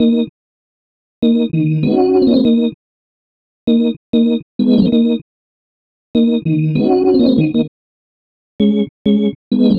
Hands Up - Organ Extra.wav